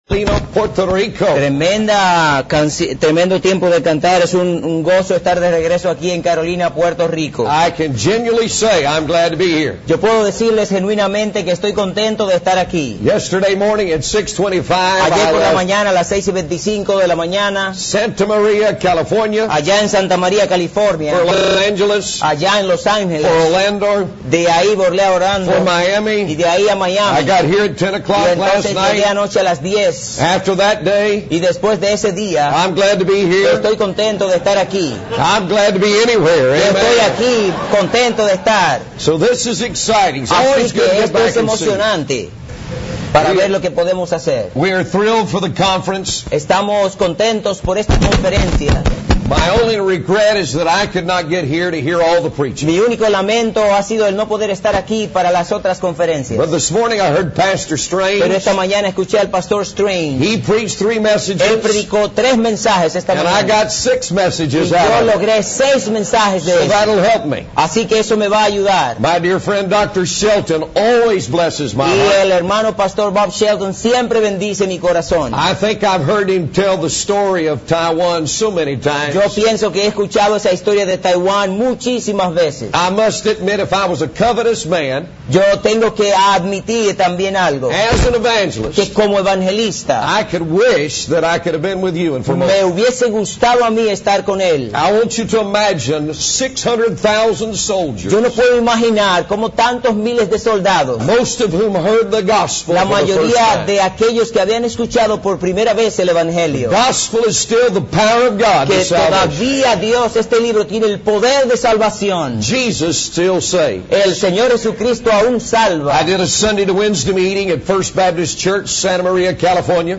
This message includes Spanish translation by an interpreter.